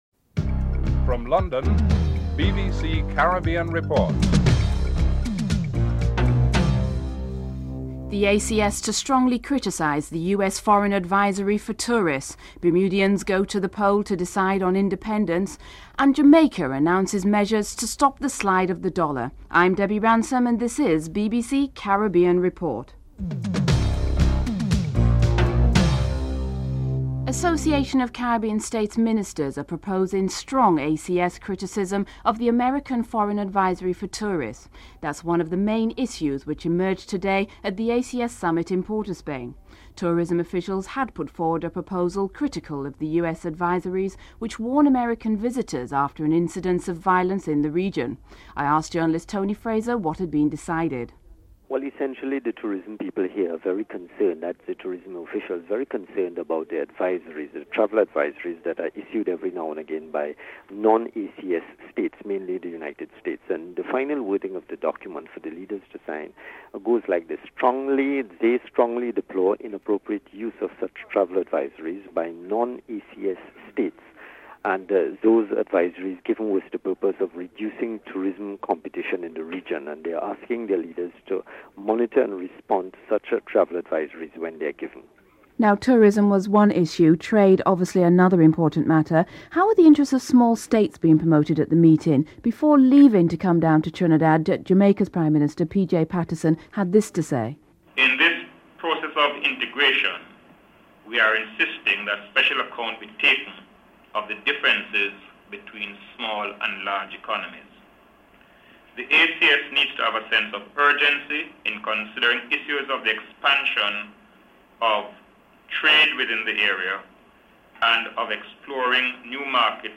In this report, the Association of Caribbean States (ACS) is proposing strong criticism of the American foreign advisory for tourists. Also, Jamaica's Prime Minister P.J. Patterson comments on whether issues of small states are likely to be a major issue at the ACS Summit.
Prime Minister James Mitchell spoke to the House at the end of the debate.